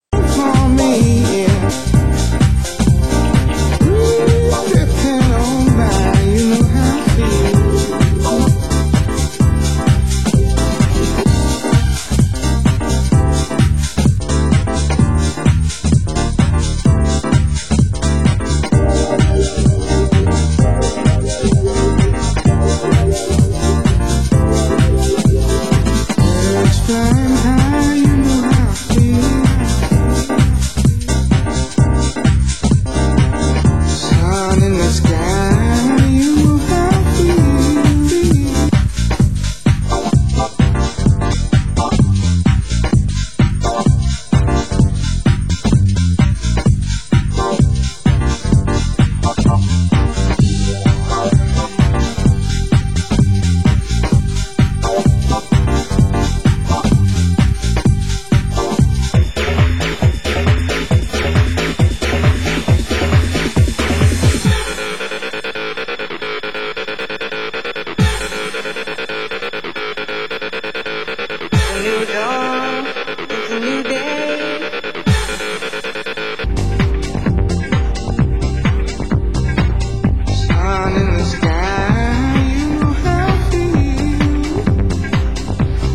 Genre: UK House